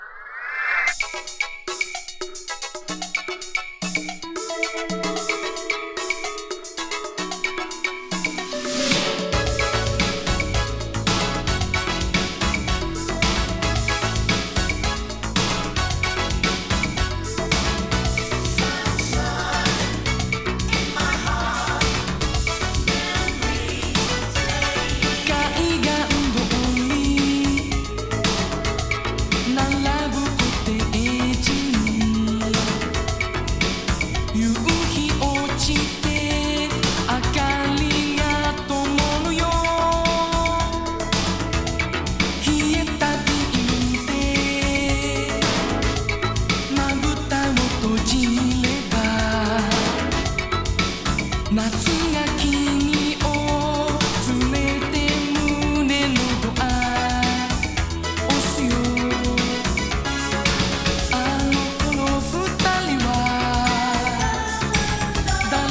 和ソウル / ディスコ / ファンク